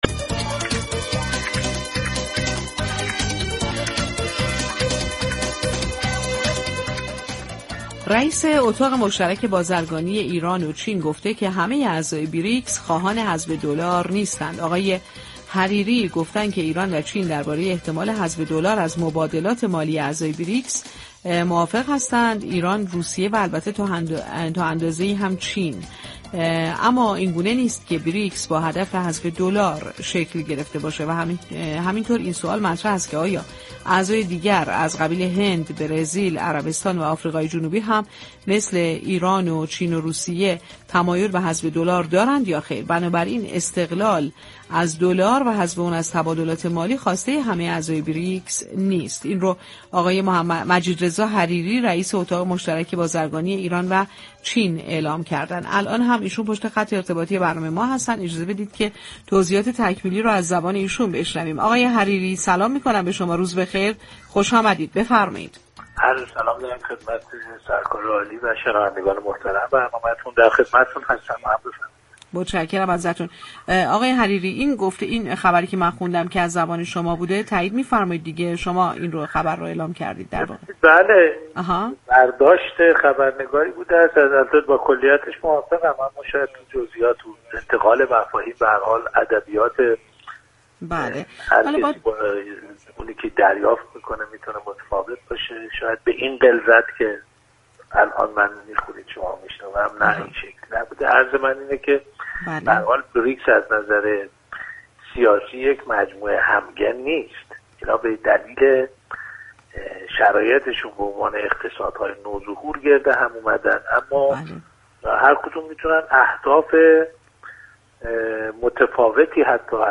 در گفتگو با رادیو تهران